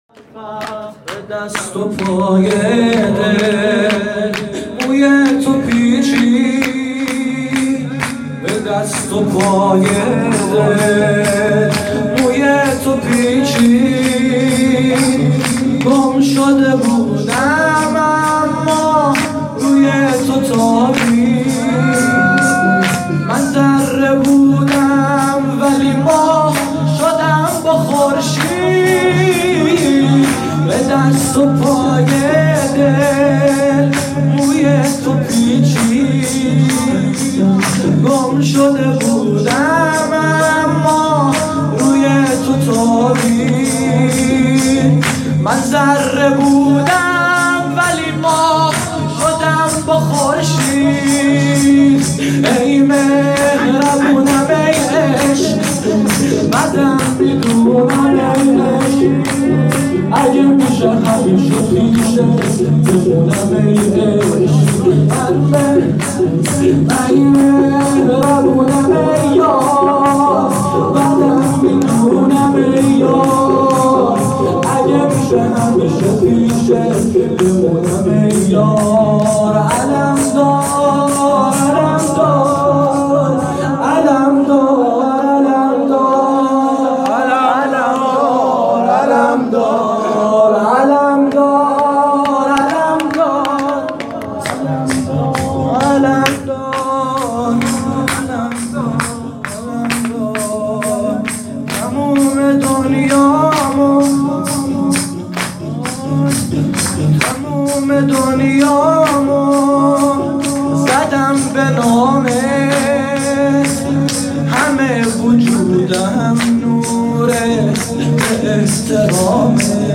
به دست و پای دل موی تو پیچید، شور